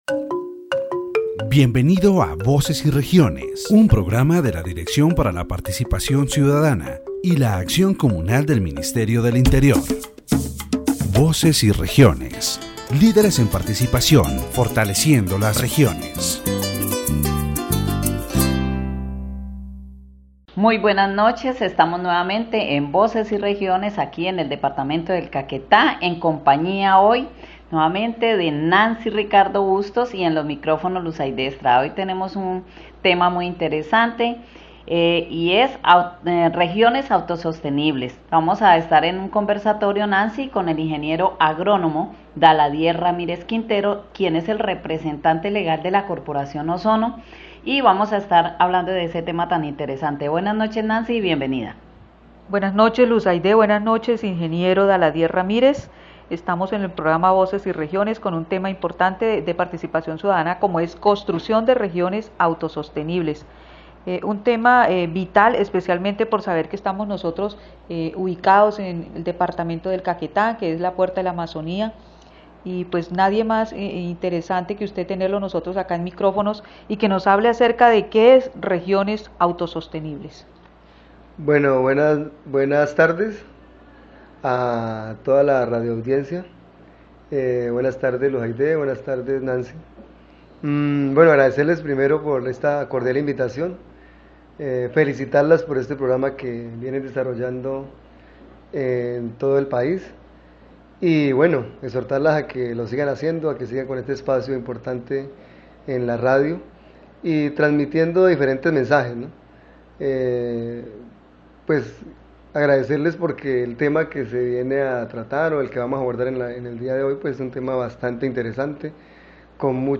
The radio program "Voices and Regions" of the Directorate for Citizen Participation and Communal Action of the Ministry of the Interior focuses on the construction of self-sustaining regions in the Department of Caquetá. During the program, progress and challenges in the implementation of sustainable practices in agriculture and livestock are discussed, highlighting the importance of agroforestry systems and the integration of ancestral knowledge. Participants share experiences and examples of success in the region, highlighting the need for public policies that support these efforts.